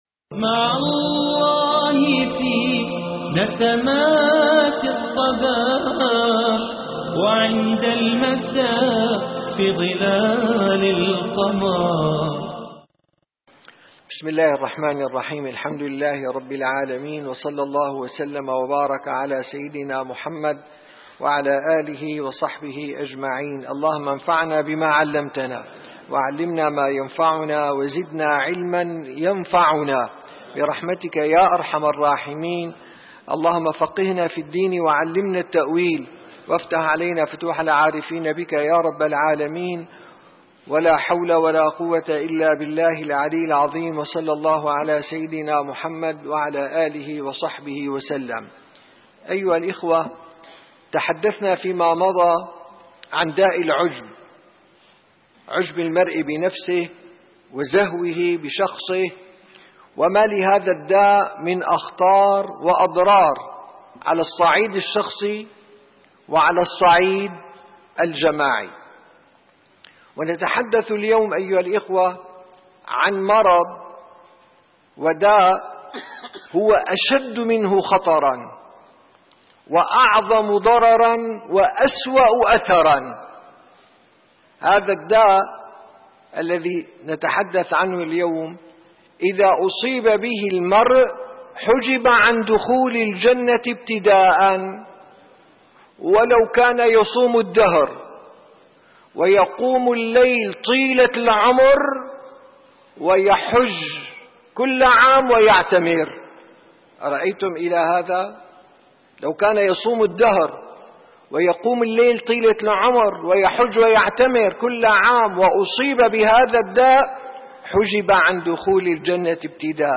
9 - درس جلسة الصفا: داء الكبر تحليله وخطره